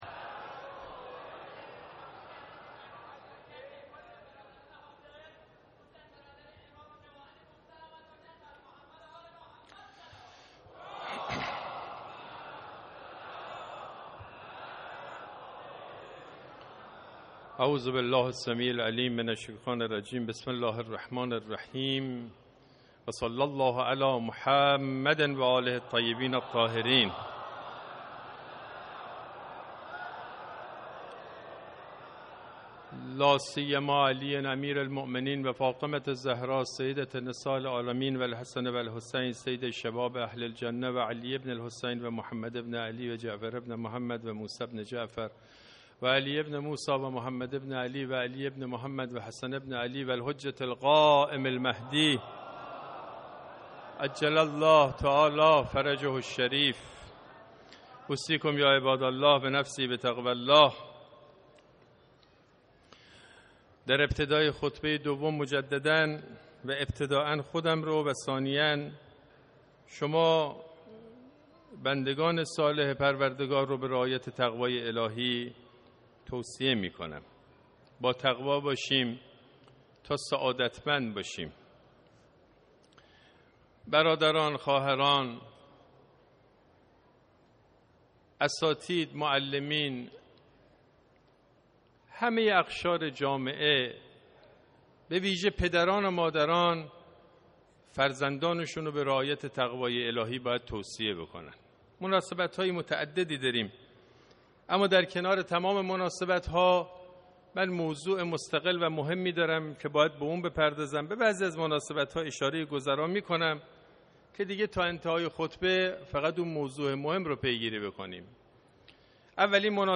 خطبه دوم